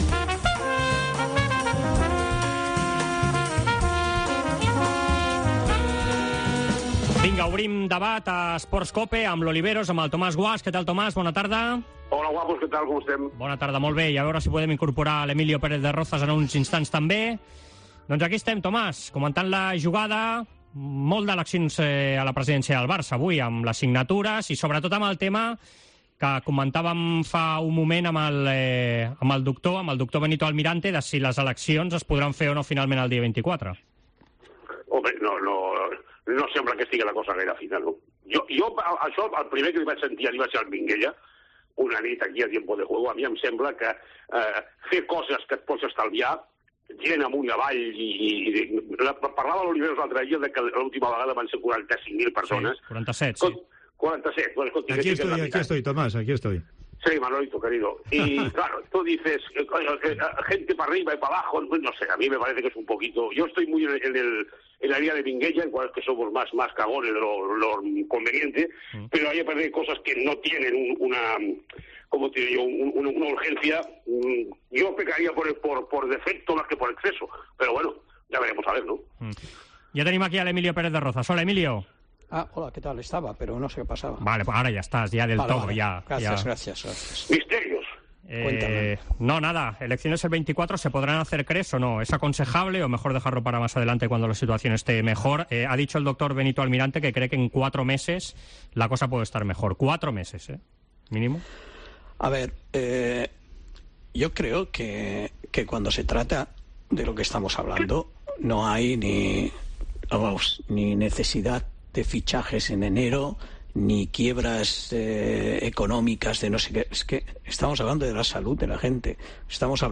scolta el 'Debat Esports COPE'